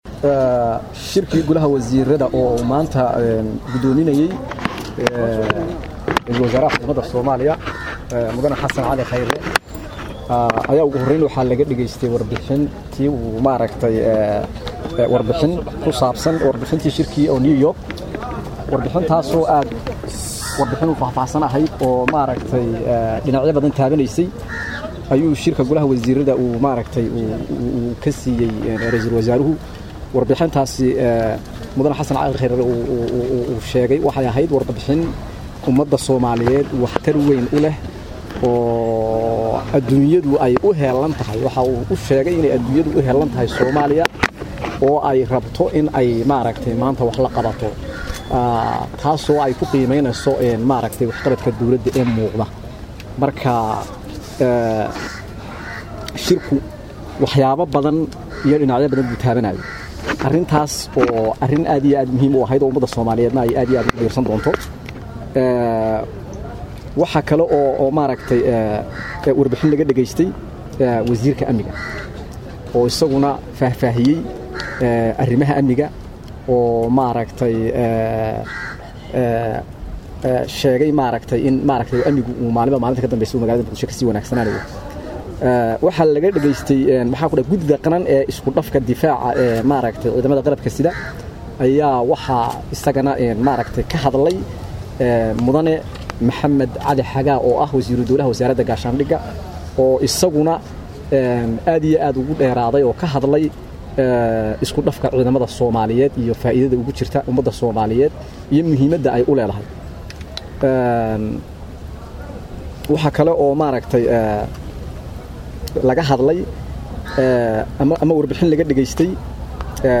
Wasiir ku xigeenka Wasaaradda Warfaafinta Xukuumadda Federaalka Soomaaliya C/raxmaan Iidaan Yoonis ayaa shirka kadib warbaahinta u faah faahiyay waxyaabihii kulanka looga hadlay.